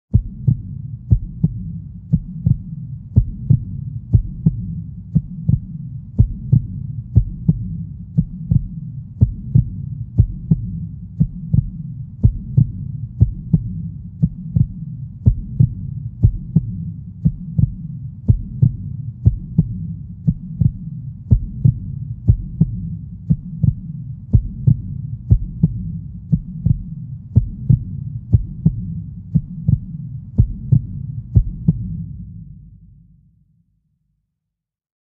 На этой странице собраны натуральные звуки человеческого тела: сердцебиение, дыхание и другие физиологические процессы.
Сердцебиение с отголоском